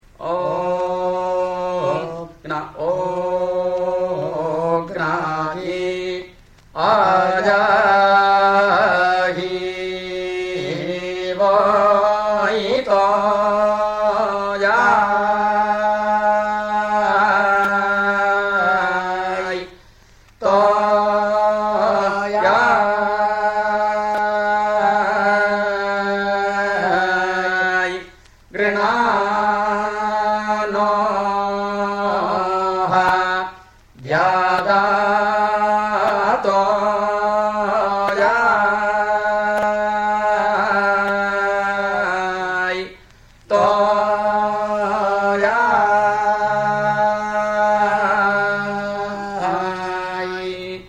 The difference is between between that of chanting and of recitation: priests chant the Samaveda with melodies that can involve a range of more than an octave.
The tradition of Sāmaveda chanting modifies the verses of the Rgveda to fit the various structures of the melodies.
00-veda3-sama-gramageya.mp3